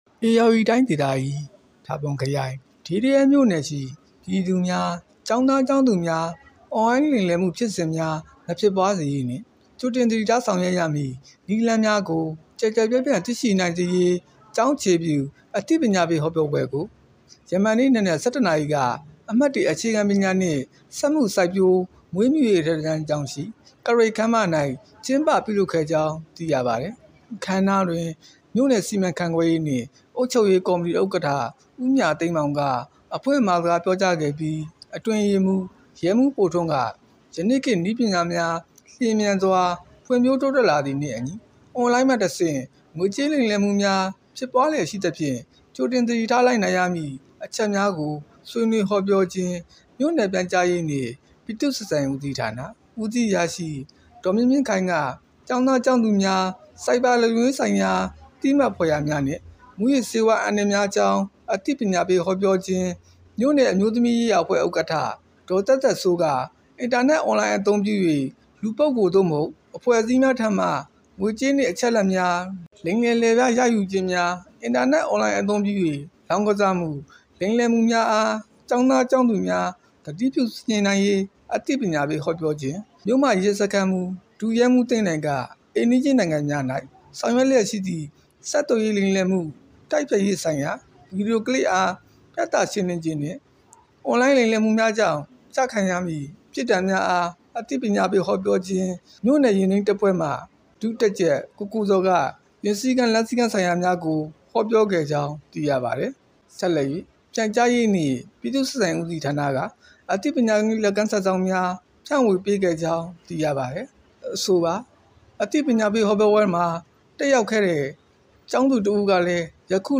အွန်လိုင်းလိမ်လည်မှုဖြစ်စဥ်များ မဖြစ်ပွားစေရေး အသိပညာပေး ဟောပြောပွဲ ကျင်းပ ဒေးဒရဲ စက်တင်ဘာ ၁၀